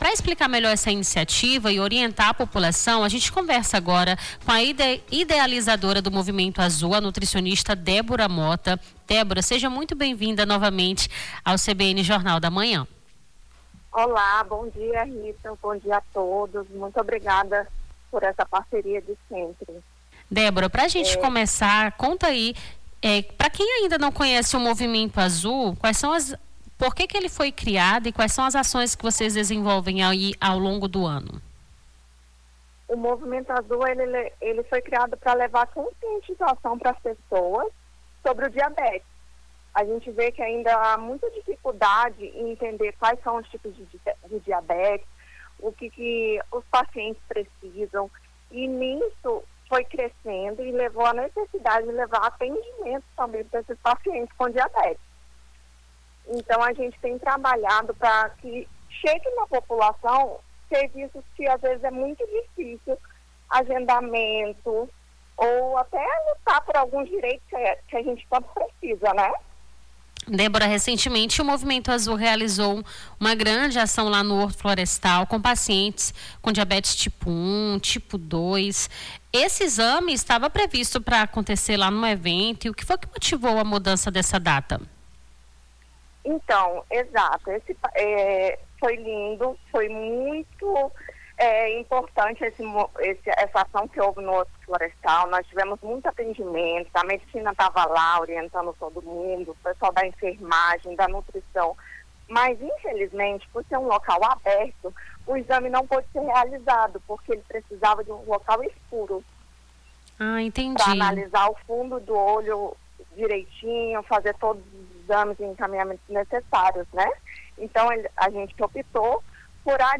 Nome do Artista - CENSURA - ENTREVISTA (ACAO DO MOVIMENTO AZUL) 09-12-25.mp3